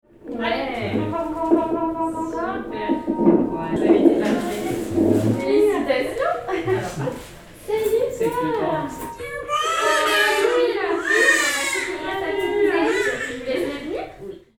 Plutôt que de nous appuyer sur des banques sonores formatées, nous privilégions la capture directe des sons sur le terrain.
CapioVox va chercher le son DANS une maternité.
Ambiance – Naissance d’un bébé